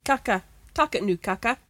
[kAK-ka: tak it noo kakka]